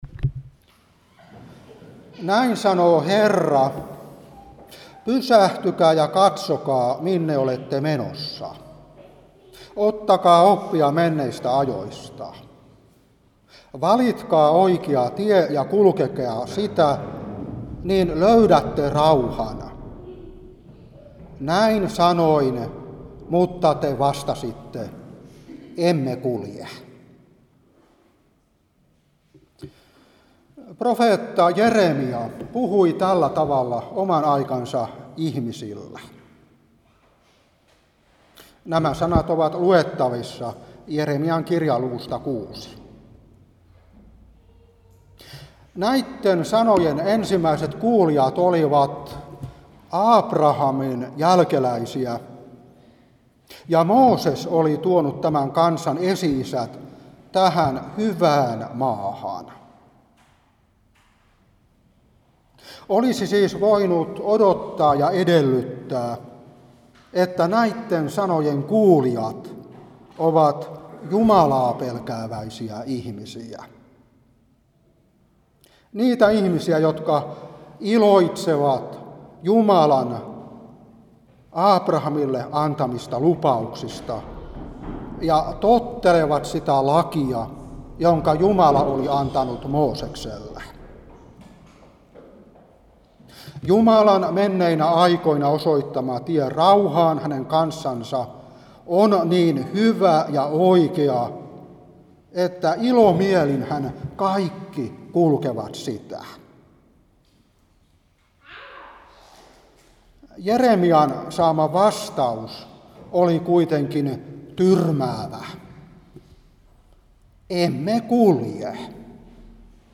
Saarna 2023-8. Jer.6:16-19. Jes.30:21. Matt.11:28. Joh.14:27.